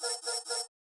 Knock Notification 9.wav